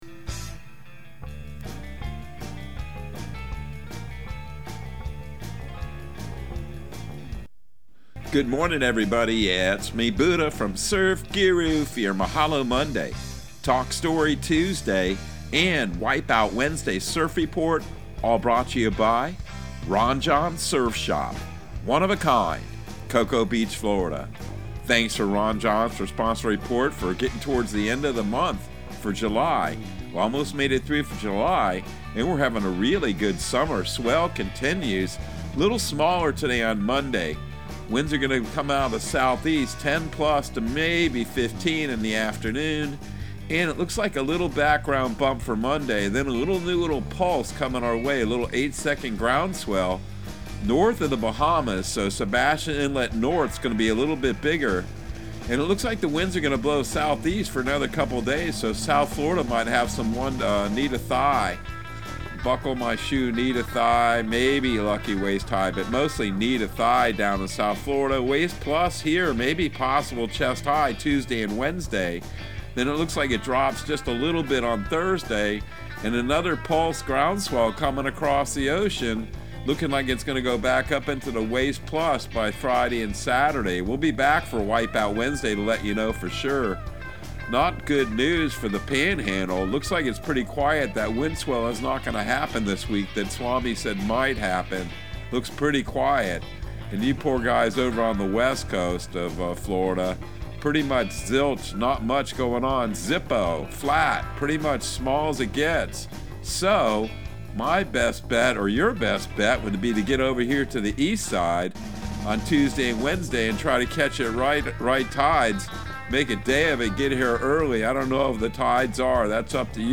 Surf Guru Surf Report and Forecast 07/25/2022 Audio surf report and surf forecast on July 25 for Central Florida and the Southeast.